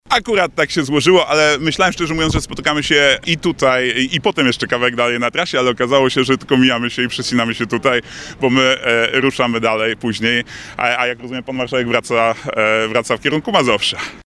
Pod koniec konferencji na placu ratuszowym pojawił się inny kandydat na prezydenta, Szymon Hołownia. Politycy przywitali się, a zapytany przez Twoje Radio, Adrian Zandberg odpowiedział, że nie było to planowane, celowe działanie.